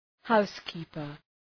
Προφορά
{‘haʋs,ki:pər}